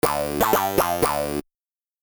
Есть данный синтезатор и хочется один из пресетов накрутить на другом синтезаторе. Название - CH Funky Chunk.